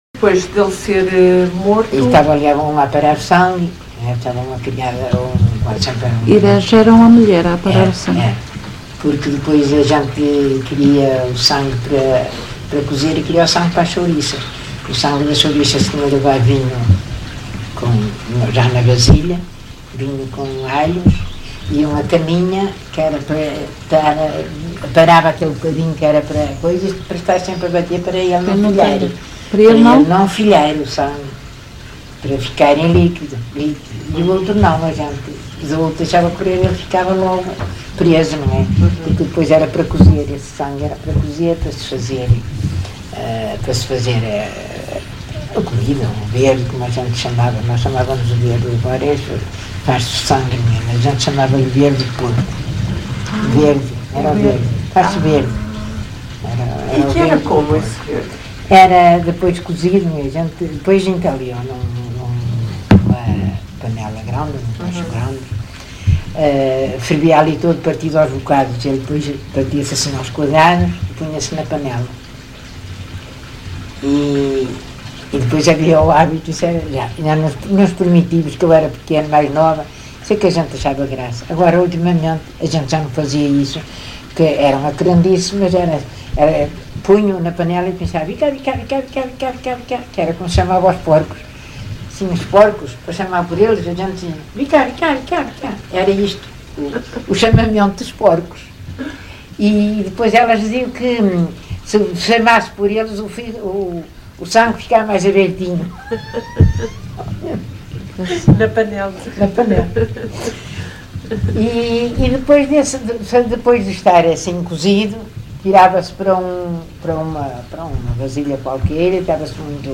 LocalidadeGião (Vila do Conde, Porto)